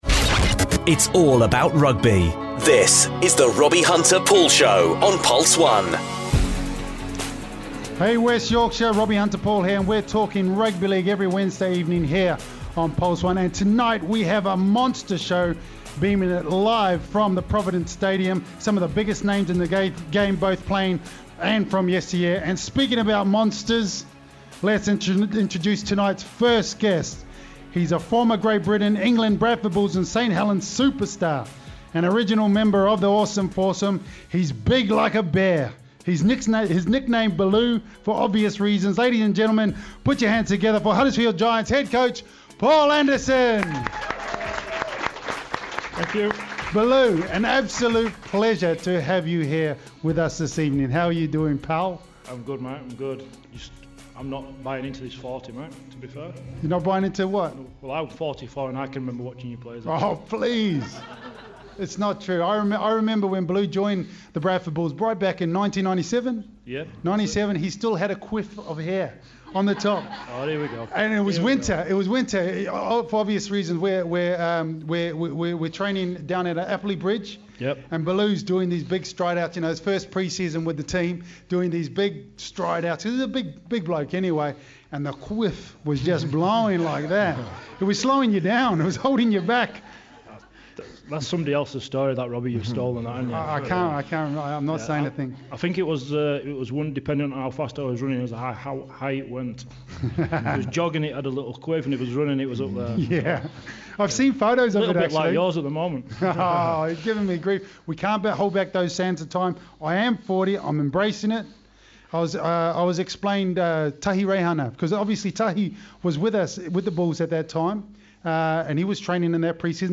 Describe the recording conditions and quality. RHP SHOW live from Provident Stadium >>